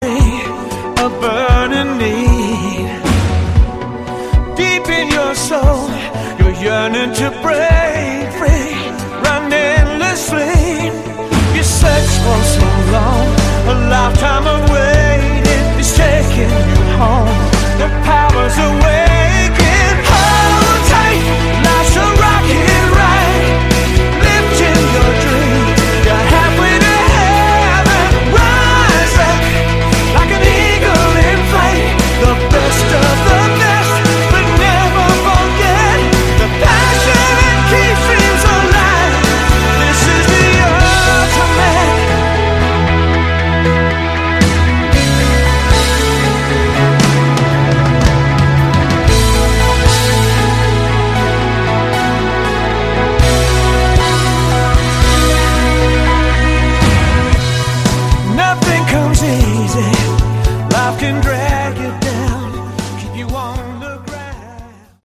Category: AOR
vocals
guitar, keyboards, bass, drums